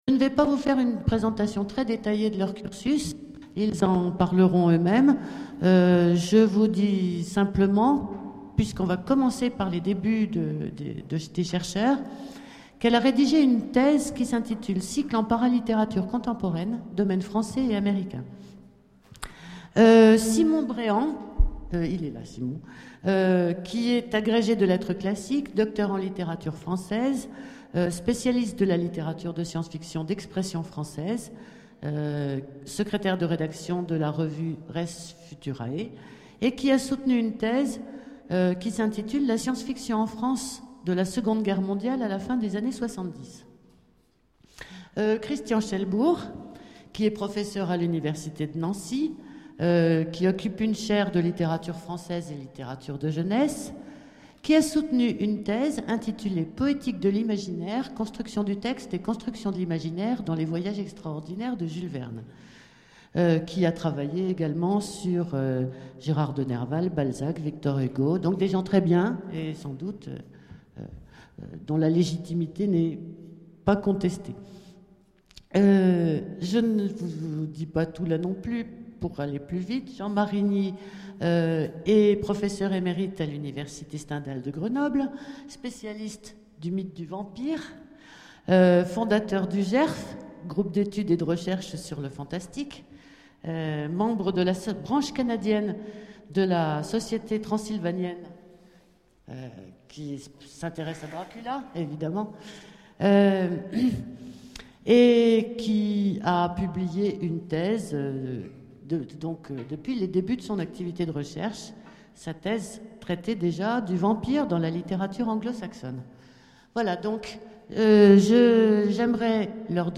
Imaginales 2013 : Conférence De l'indifférence à l'ouverture...